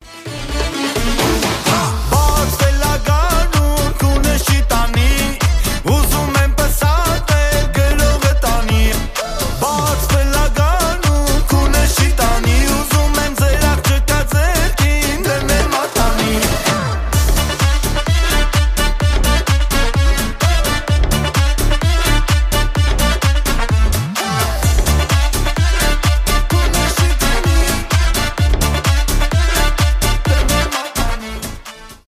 танцевальные , веселые , фолк , армянские